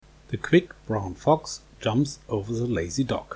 Mikrofon Test
Von dem winzigen Inline Mikrofon haben wir natürlich keine Studioqualität zu erwarten, aber der Klang ist erstaunlich klar und rauscharm.